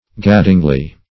gaddingly - definition of gaddingly - synonyms, pronunciation, spelling from Free Dictionary Search Result for " gaddingly" : The Collaborative International Dictionary of English v.0.48: Gaddingly \Gad"ding*ly\, adv. In a roving, idle manner.